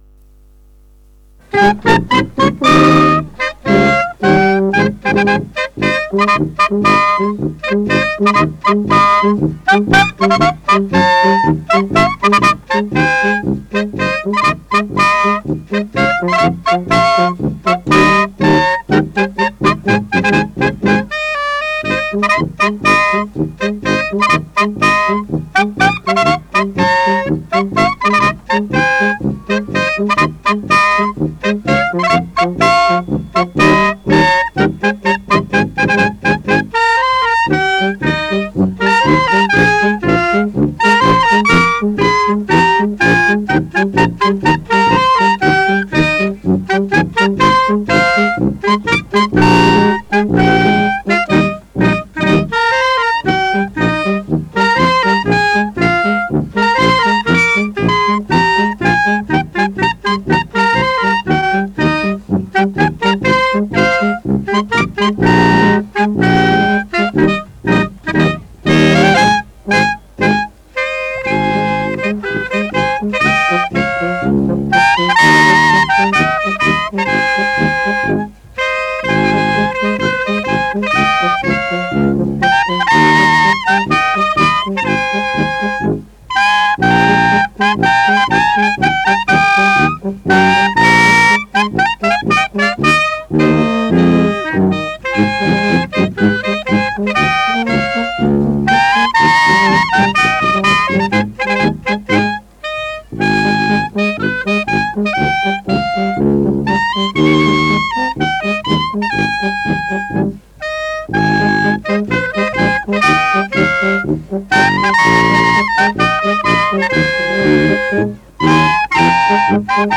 Hirtenmusik in Europa